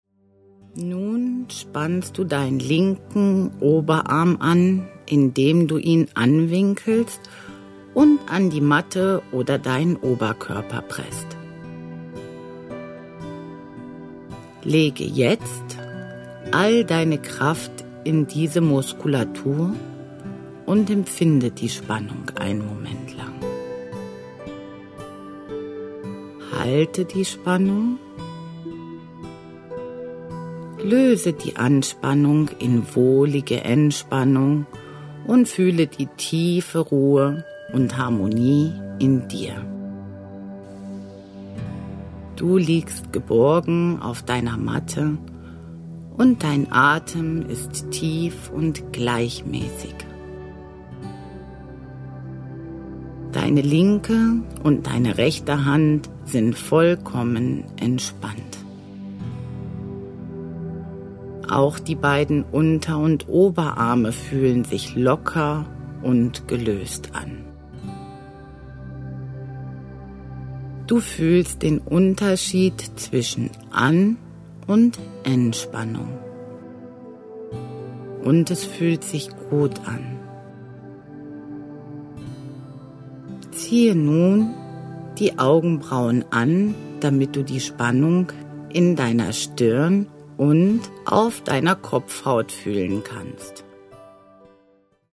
Hörbuch kaufen: